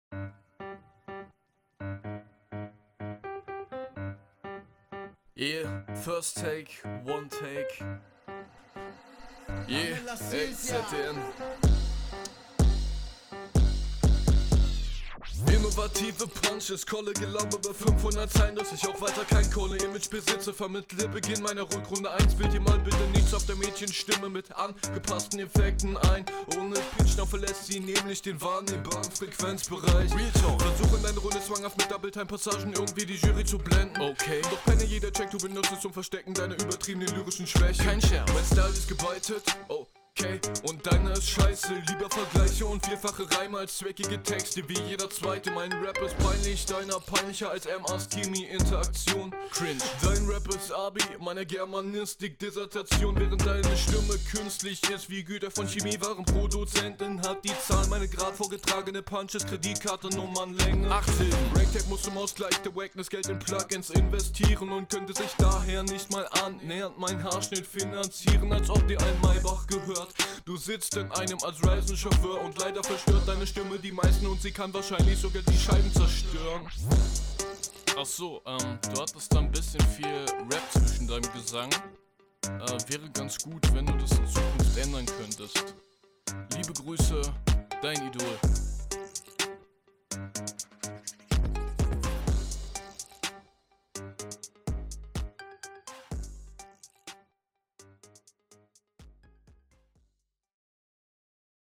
Deine Audio klingt bei 100% wie auf 50%.
First take, one take..
Flowlich wieder solide.